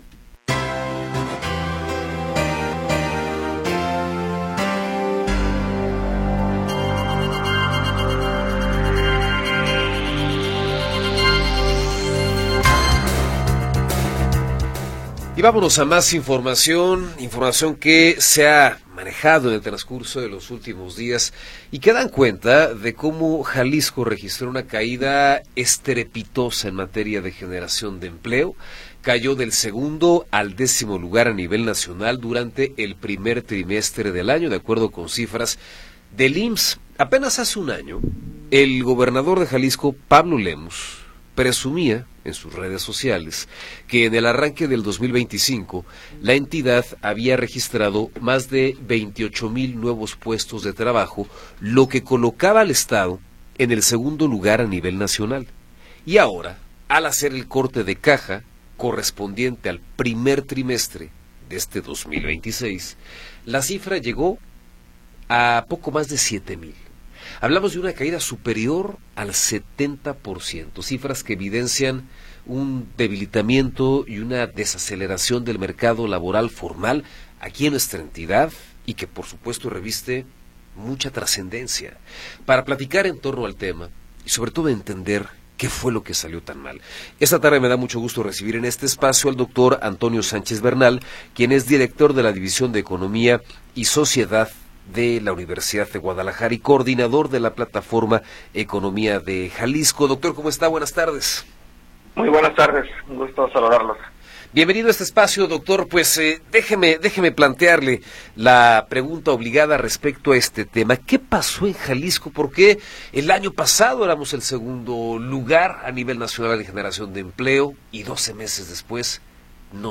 Entrevista.m4a